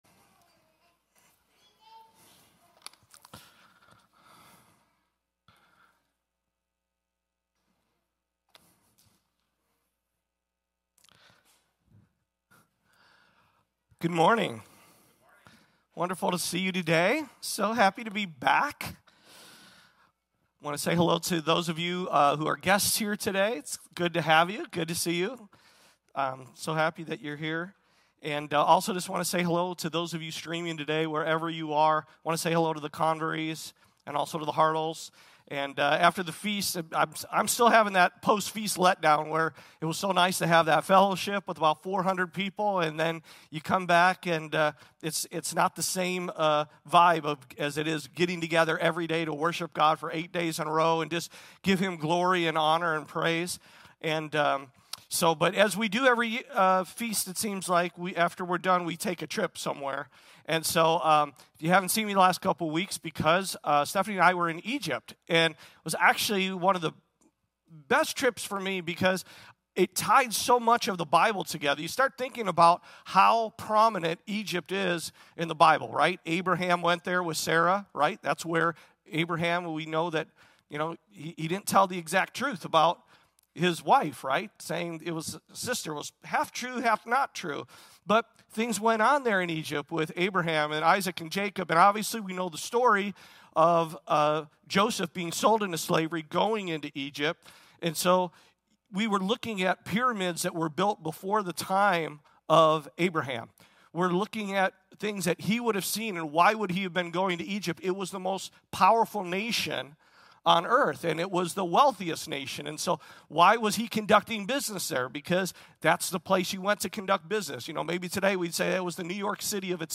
- Sabbath Christian Church | Rock Valley Christian Church